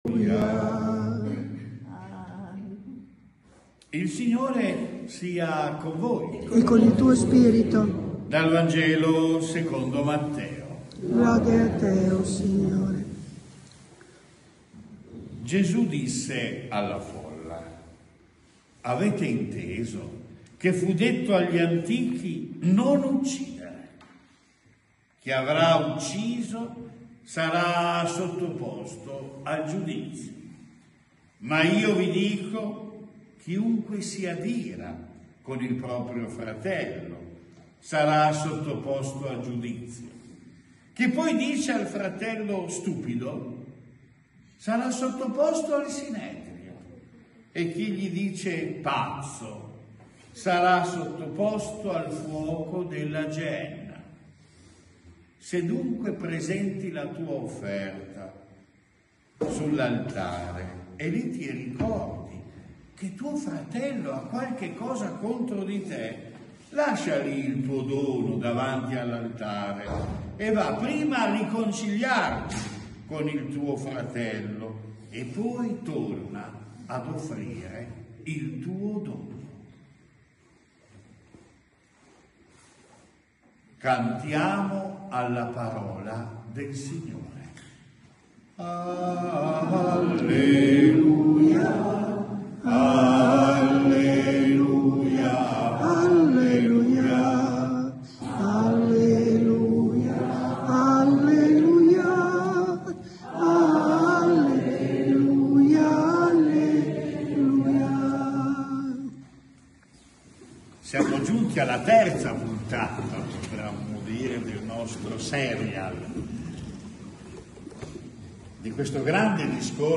Omelia VI dom.